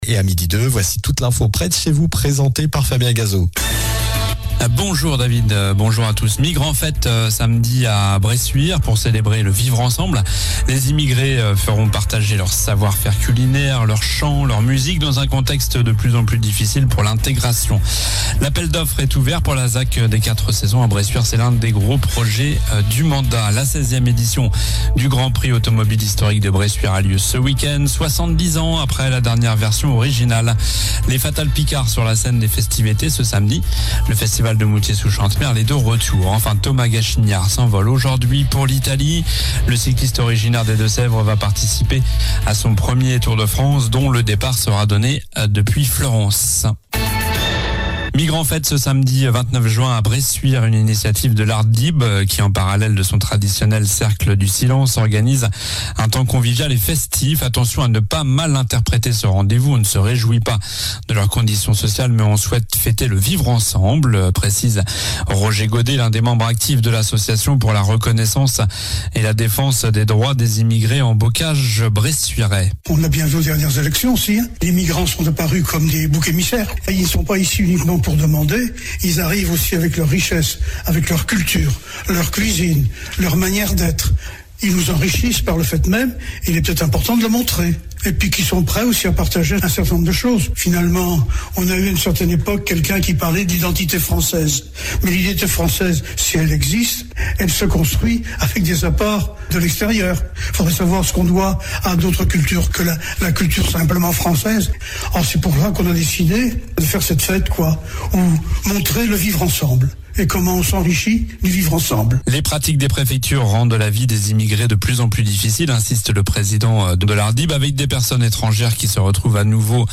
Journal du mercredi 26 juin (midi)